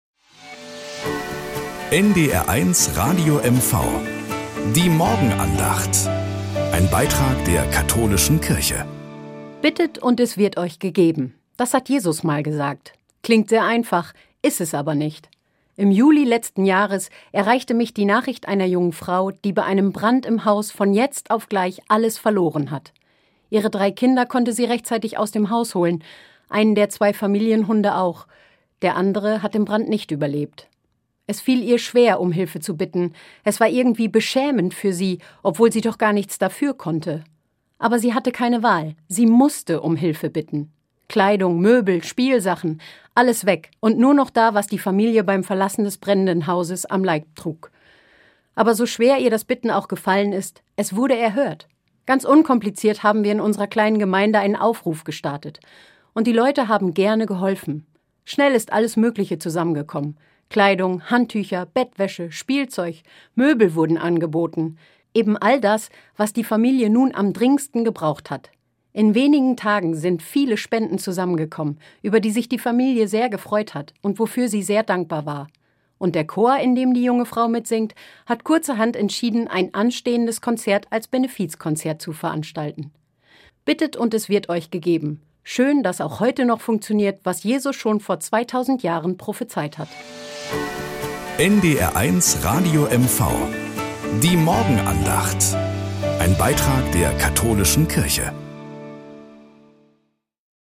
Morgenandacht bei NDR 1 Radio MV